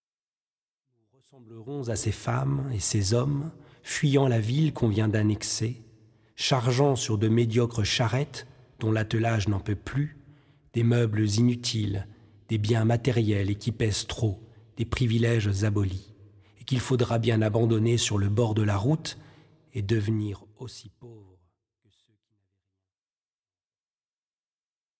Lento (1.29 EUR)